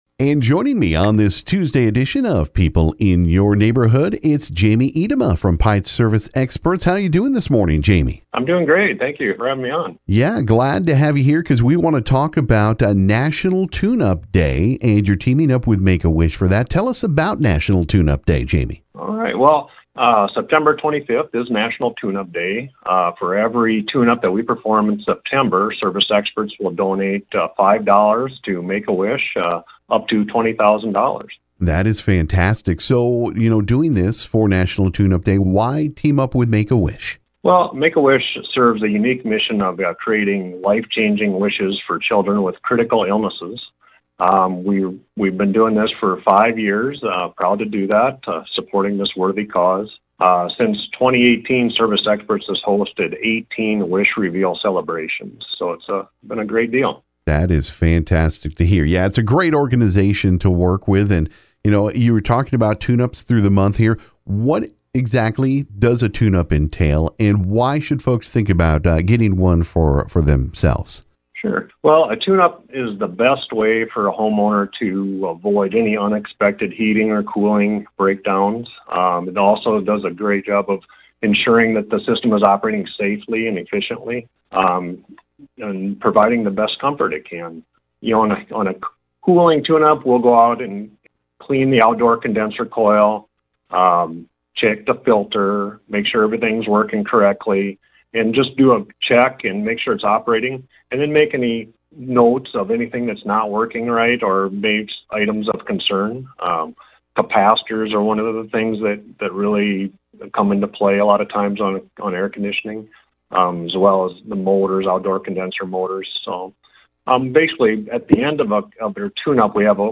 This morning on KGFX